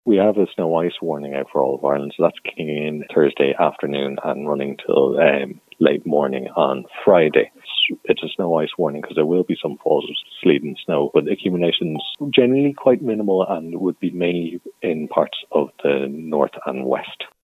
Forecaster